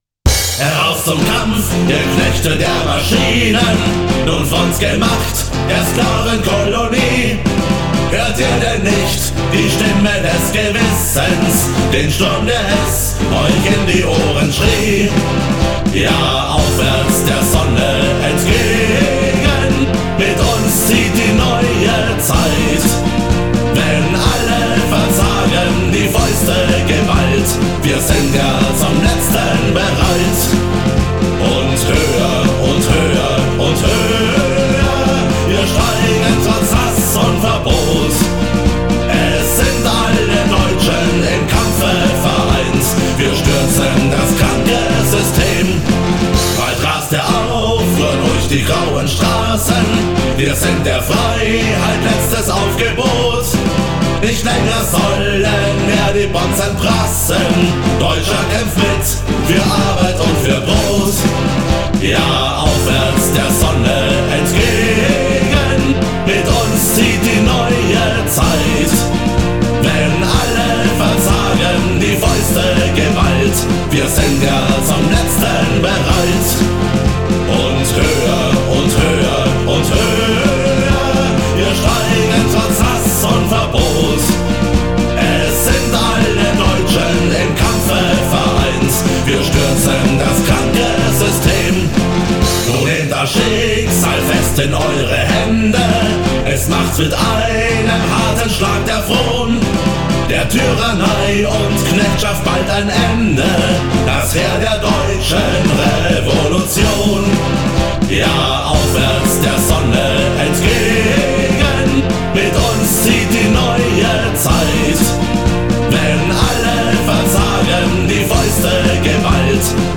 в современном немецком исполнении